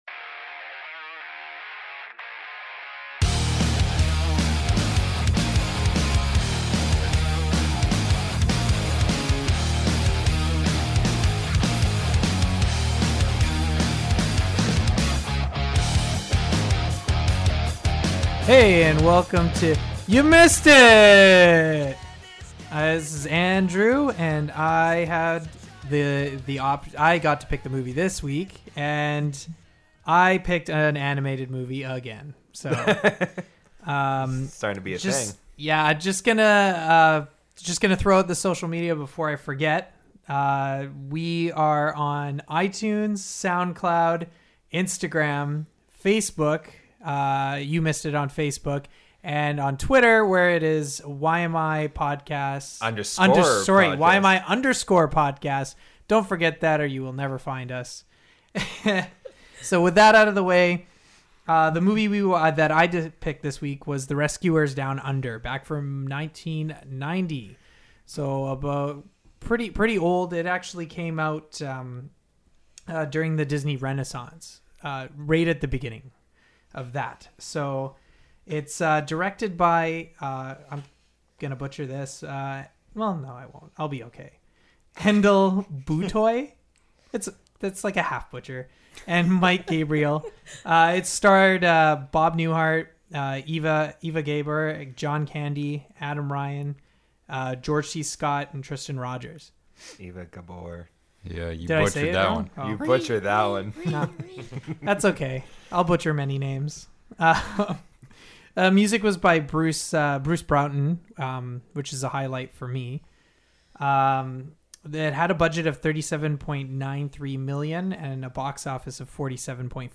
Each episode, one of the hosts selects a movie to watch that they feel has not received the credit it deserves; afterwards everyone discusses and offers their opinions and their analyses to determine whether or not the movie is indeed underrated.